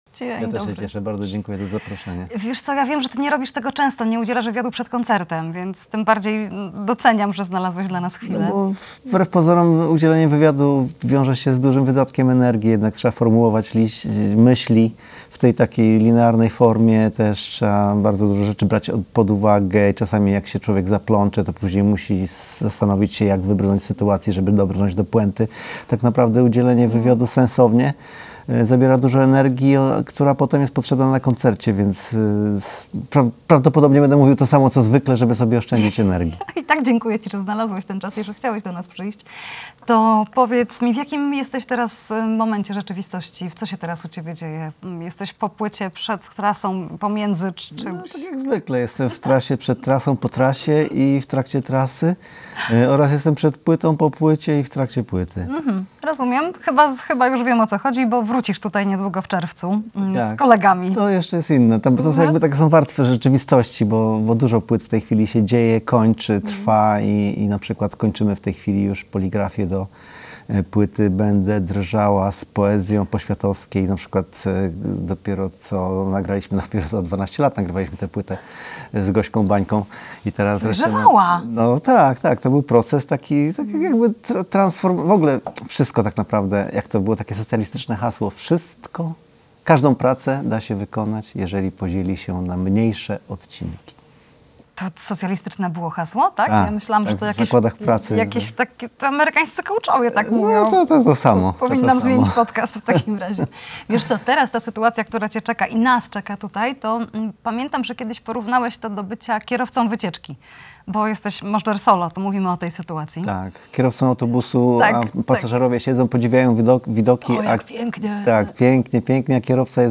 Jazz a vu: Rozmowa z Leszkiem Możdżerem [POSŁUCHAJ]